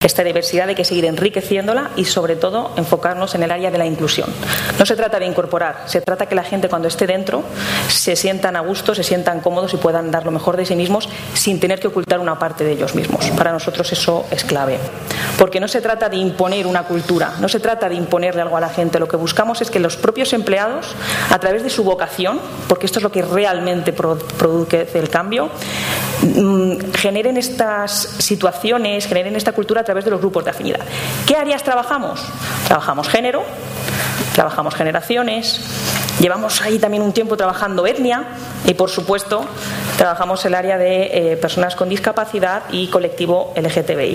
Mesa redonda por la diversidad transversal